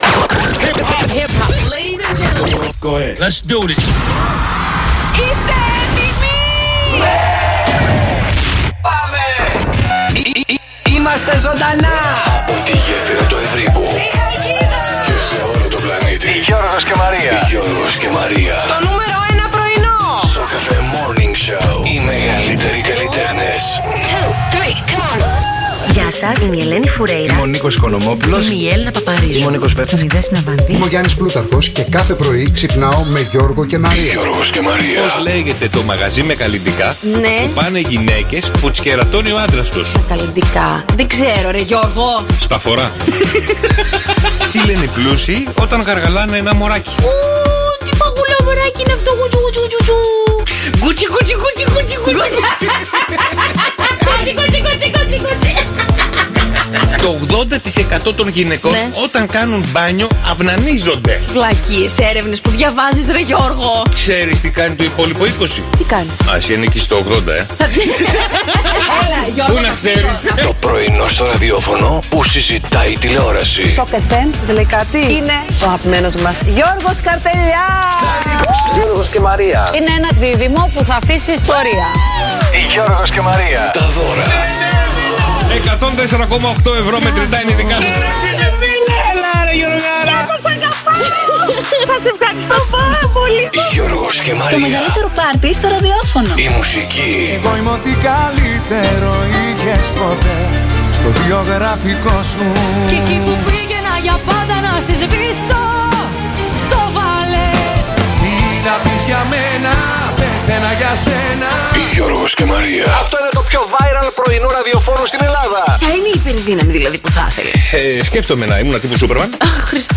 Οι ακροατές μας τραγουδούν επιτυχίες